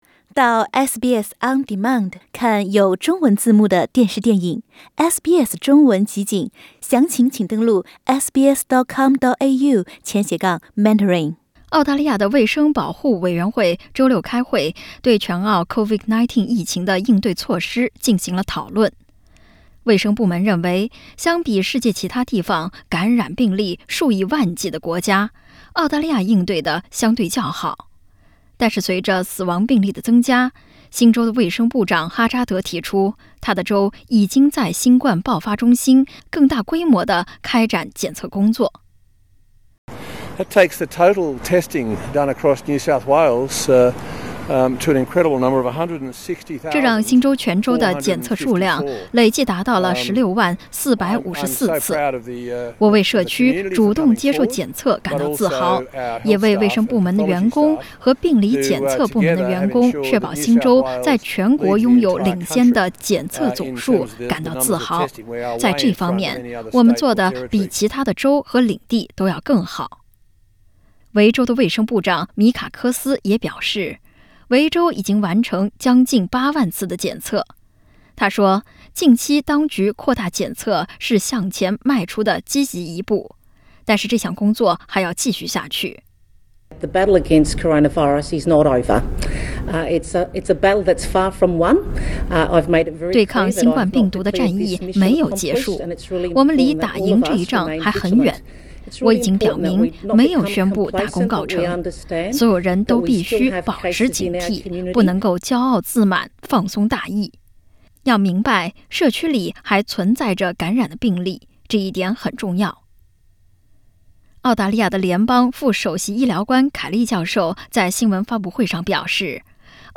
【COVID-19报道】卫生官员评估澳洲抗疫措施：扩大检测和追踪感染是未来重点